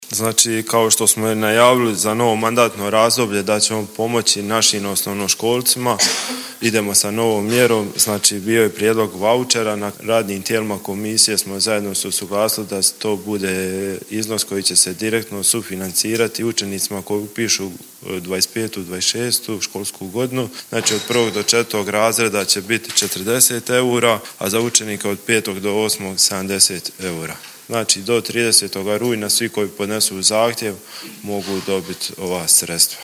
Gradonačelnik Drniša Tomislav Dželalija:
Dzelalija-izjava-pomoc-ucenicima.mp3